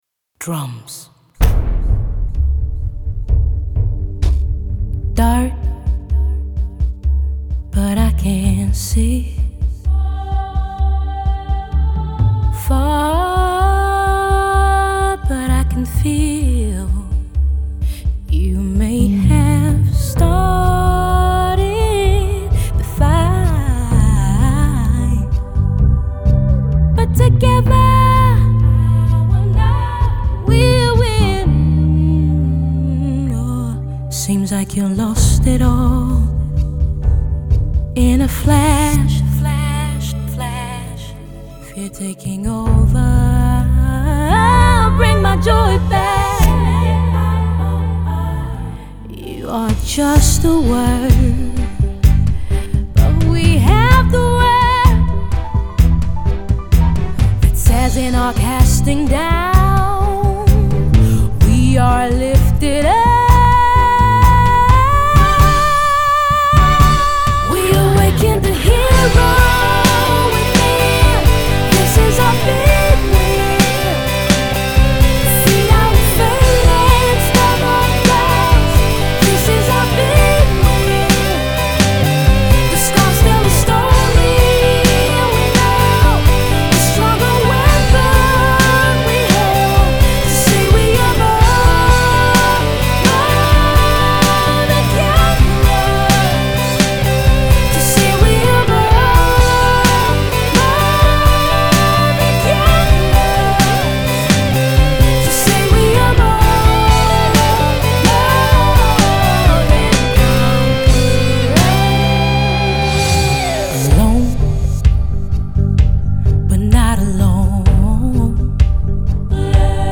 electrifying yet inspiring, creative piece